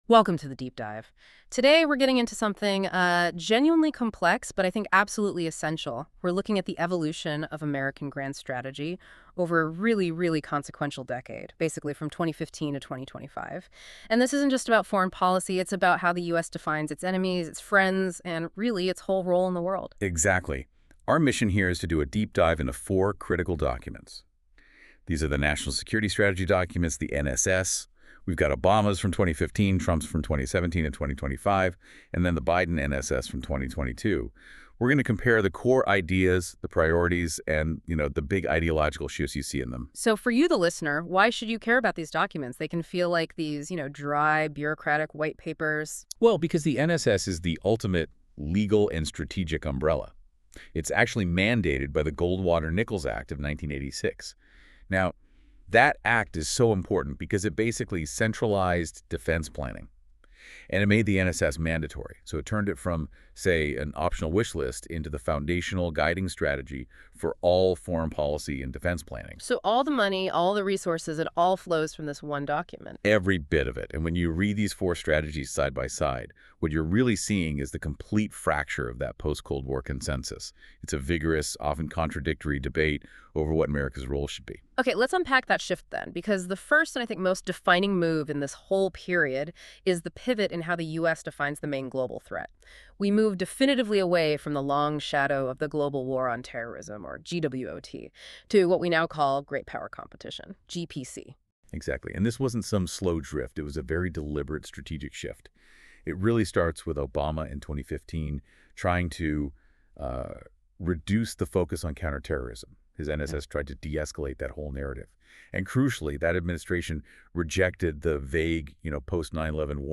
These podcast episodes are produced with AI voice technology. While we strive for accuracy, please be aware that the voices and dialogue you hear are computer-generated.